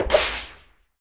Block Lava Sound Effect
block-lava-2.mp3